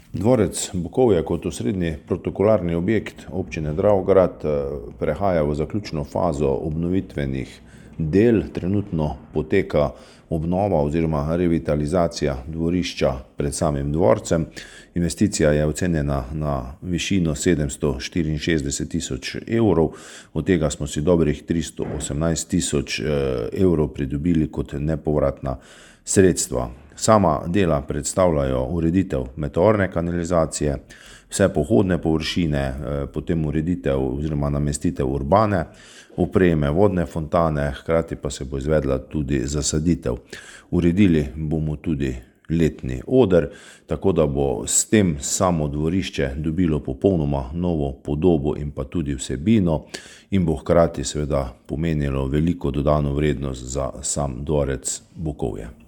Dvorišče pri dvorcu Bukovje bo kmalu zaživelo v novi podobi. V kakšni, je pojasnil župan občine Dravograd Anton Preksavec:
izjava Preksavec - obnova Bukovje za splet.mp3